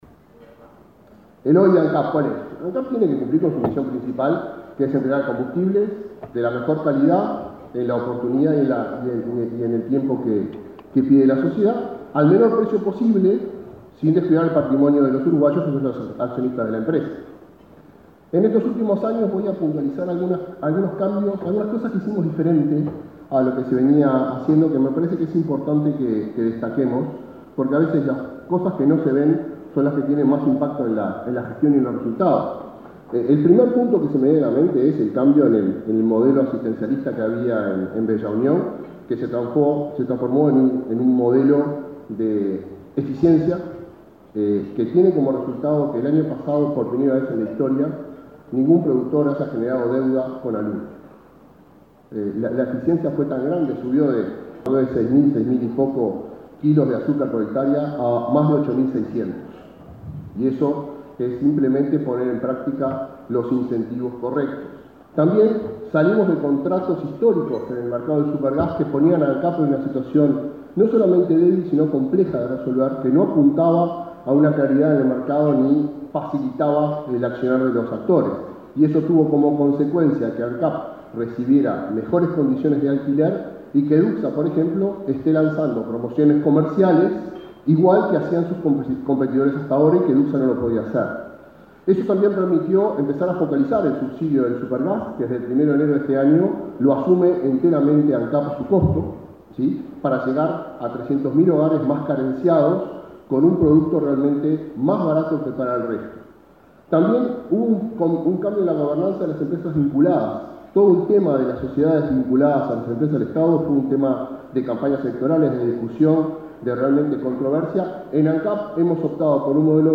Disertación del presidente de Ancap, Alejandro Stipanicic
Disertación del presidente de Ancap, Alejandro Stipanicic 04/07/2023 Compartir Facebook X Copiar enlace WhatsApp LinkedIn El presidente de Ancap, Alejandro Stipanicic, disertó este martes 4 en Montevideo, en un desayuno de trabajo organizado por la Asociación de Dirigentes de Marketing.